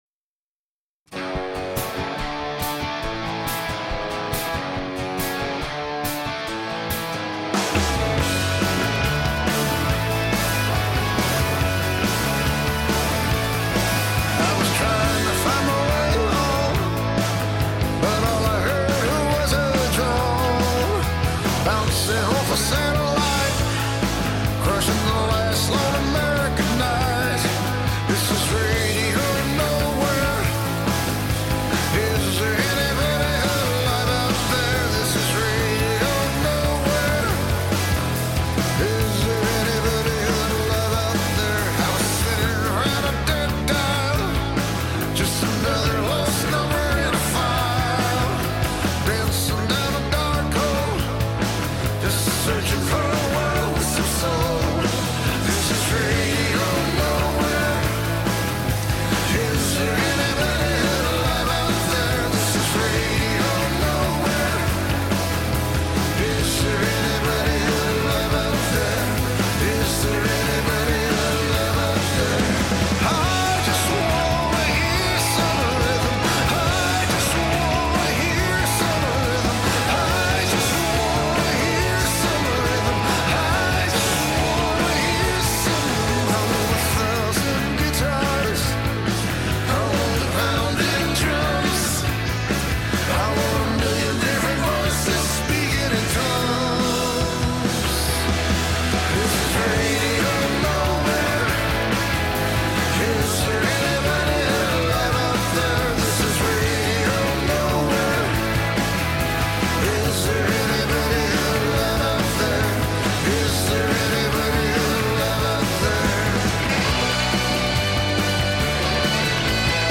آهنگ ها ملوديک هستند و ملودي ها رمانتيک.
با ضرب تند و هجوم گيتارها.